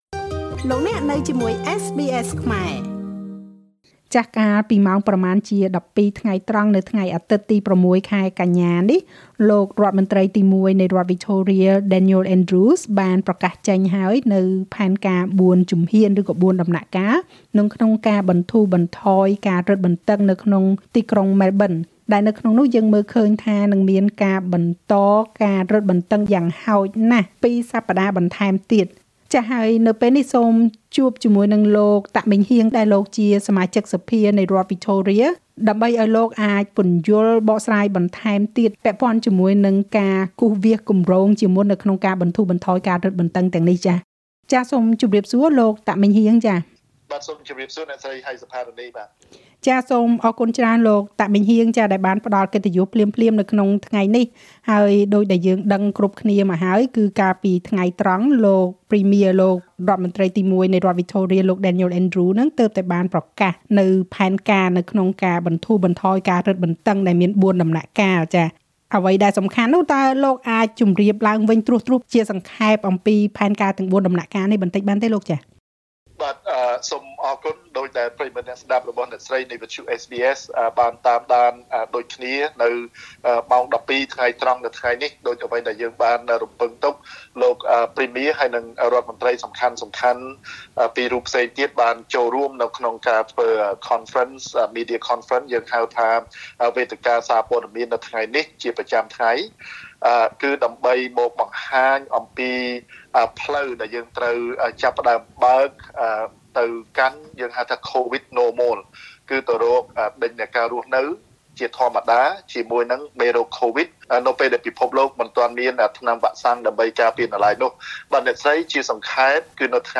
ក្នុងនោះយើងបានឃើញមានការបិទទ្វារ ឬ lockdown យ៉ាងហោចណាស់ពីរសប្តាហ៍បន្ថែមទៀត។ លោក តាក ម៉េងហ៊ាង សមាជិកសភានៃរដ្ឋវិចថូរៀ ពន្យល់បន្ថែម។
Victorian MP Meng Heang Tak provides update on Covid-19 Source: Supplied